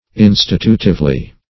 Search Result for " institutively" : The Collaborative International Dictionary of English v.0.48: Institutively \In"sti*tu`tive*ly\ adv.